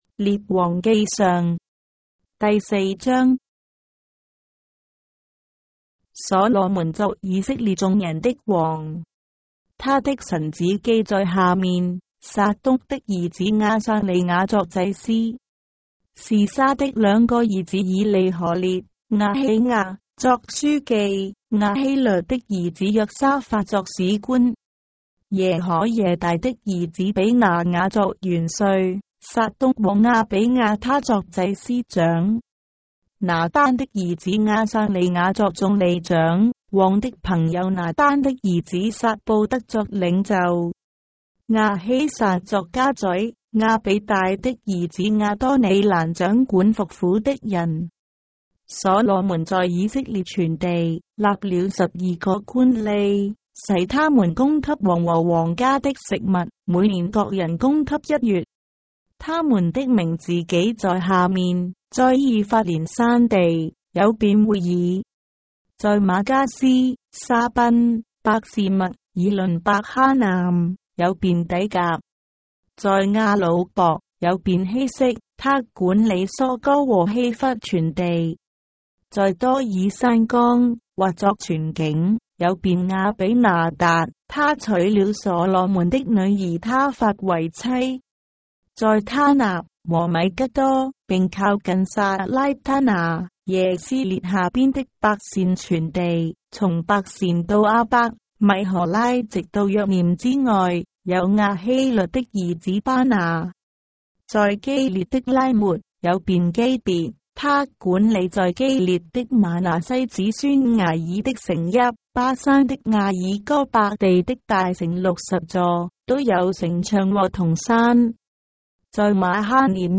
章的聖經在中國的語言，音頻旁白- 1 Kings, chapter 4 of the Holy Bible in Traditional Chinese